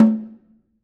Snare2-HitNS_v5_rr2_Sum.wav